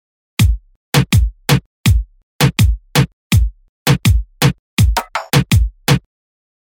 1：ハイハット・クローズ追加
バスドラと同じタイミングでハイハットを鳴らすパターンです。
印象は上記の基本パターンと変わりませんが、バスドラと同時に高音がなることで、抜けが良くスッキリした全体像になります。
reggaeton2.mp3